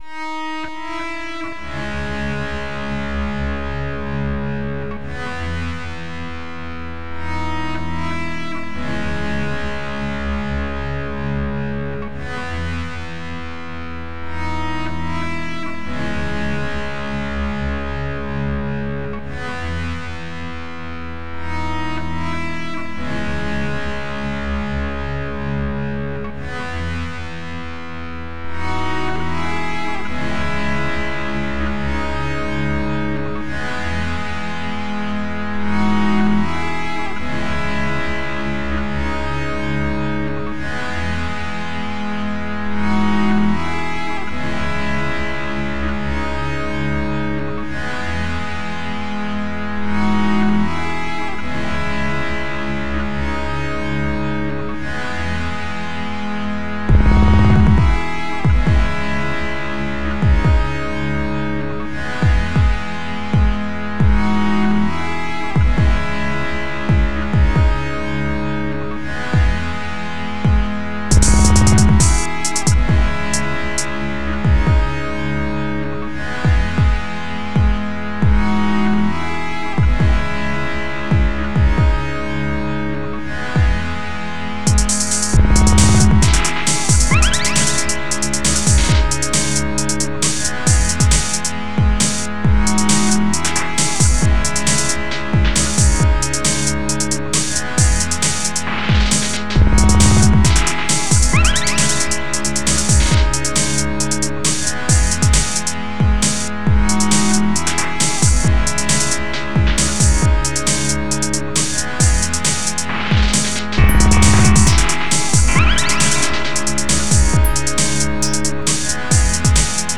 фастрекер... отрендерил из под ренойза... не все правильно встало ....